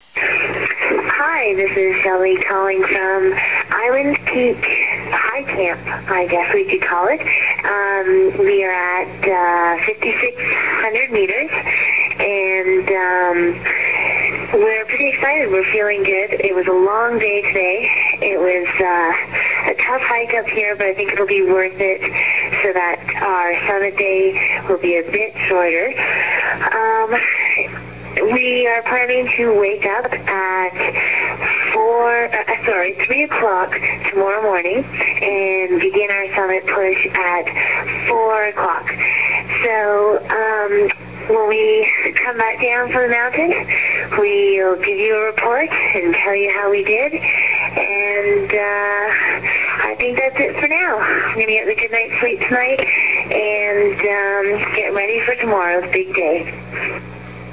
May 19 – Island Peak Team Reporting from High Camp